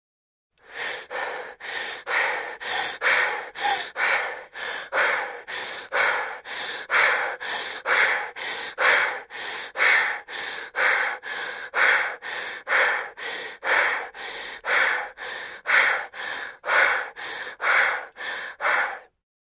Звуки вздоха человека
Девушка стремительно втягивает воздух